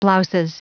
Prononciation du mot blouses en anglais (fichier audio)
Prononciation du mot : blouses